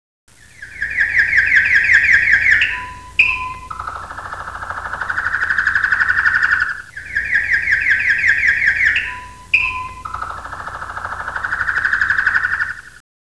カナリア（南海電気鉄道株式会社提供）
oto_guidekanaria.wav